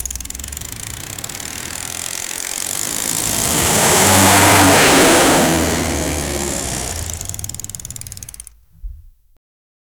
Royalty-free bmx sound effects
bmx-wheels-landing-hhkv7g65.wav